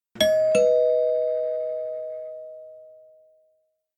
Doorbell